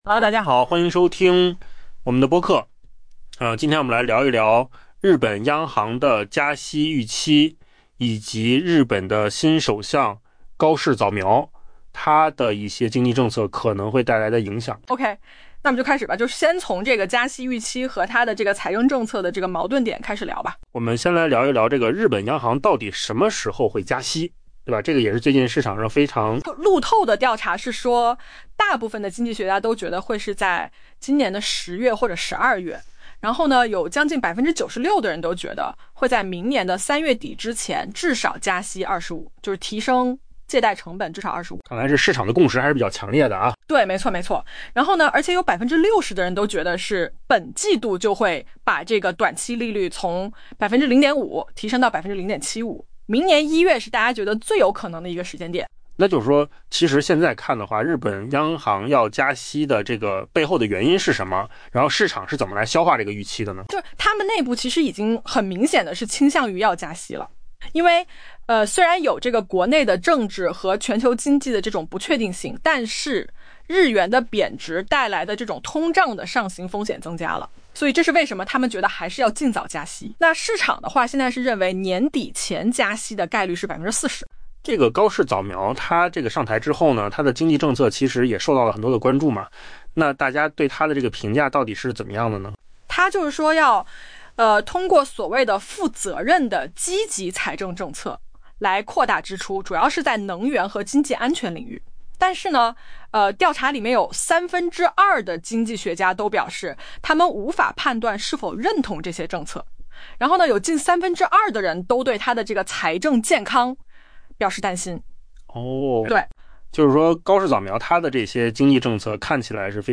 【文章来源：金十数据】AI播客：换个方
AI 播客：换个方式听新闻 下载 mp3 音频由扣子空间生成 路透社一项调查显示， 多数经济学家认为日本央行将在 10 月或 12 月上调关键利率；另有近 96% 的经济学家预计，到明年 3 月底，日本借贷成本至少会上升 25 个基点。